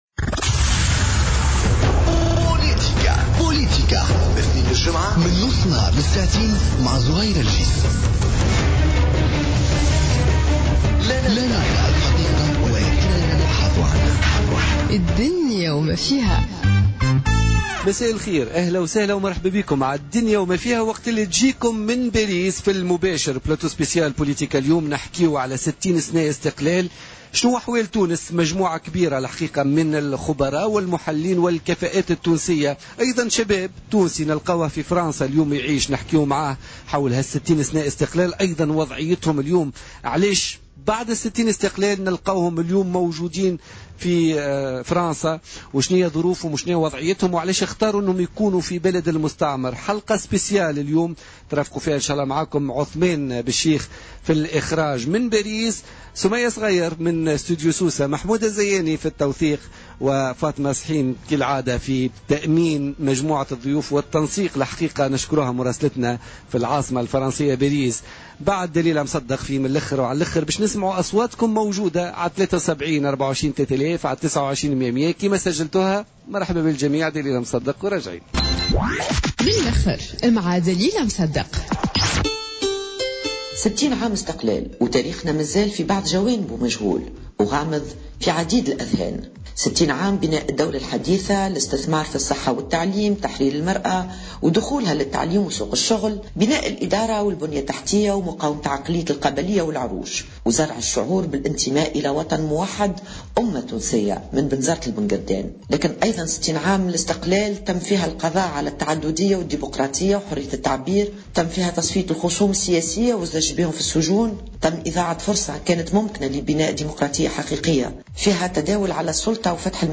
بث مباشر من باريس : عيد الإستقلال و وضعية الجالية التونسية بالخارج